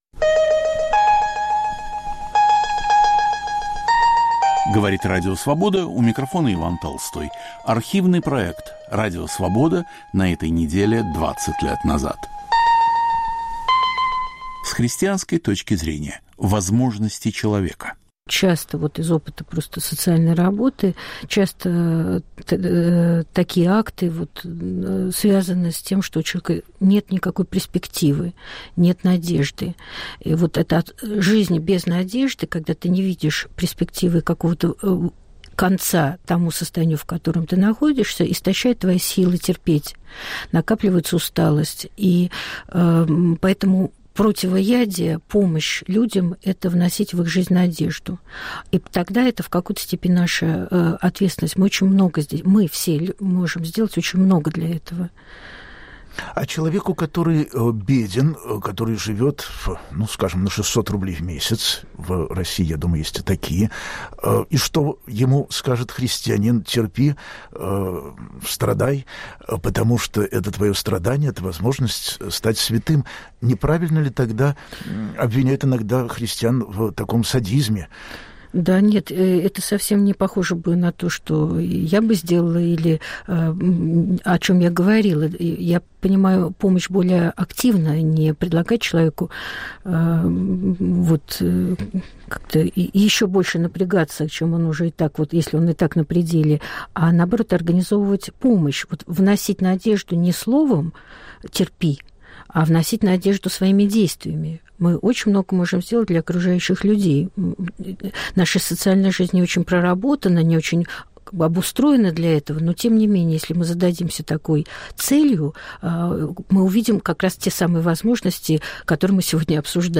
В студии психологи